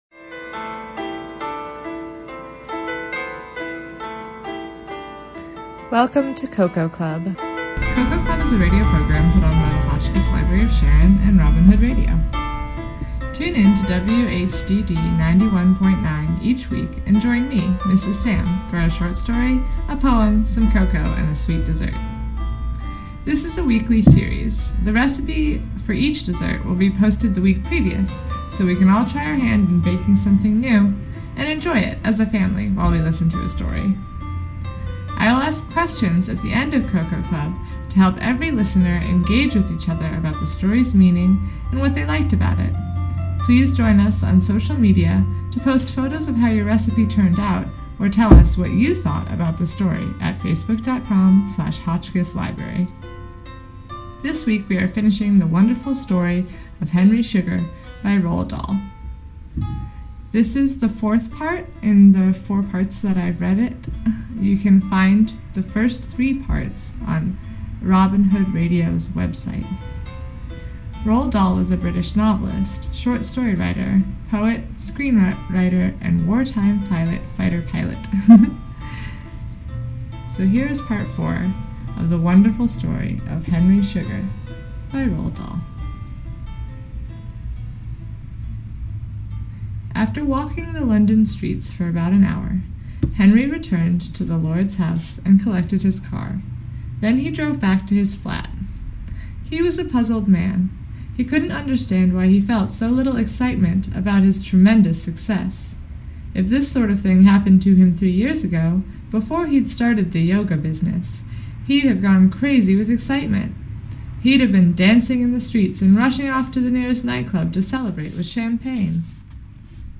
Hotchkiss-Library-Of-Sharon-Cocoa-Club-Reading-Henry-Sugar-Part-4.mp3